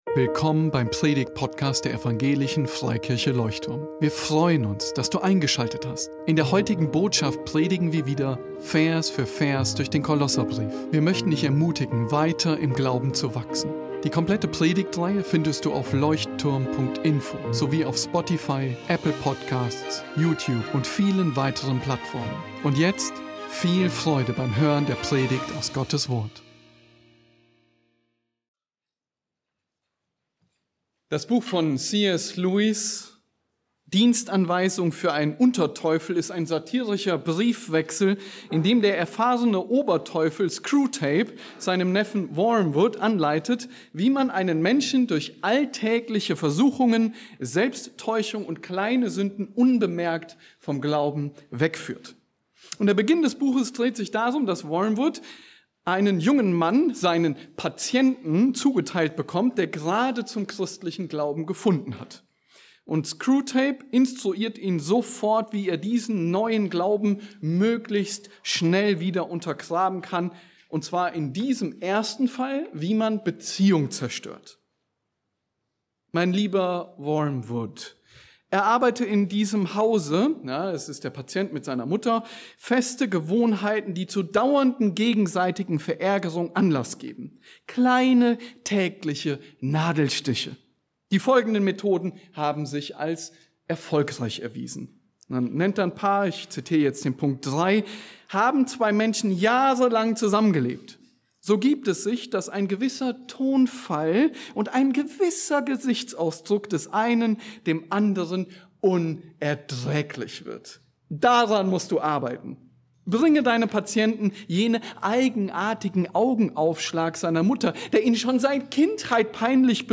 Wie du Beziehungen stärkst und rettest (oder schwächst und zerstörst) ~ Leuchtturm Predigtpodcast Podcast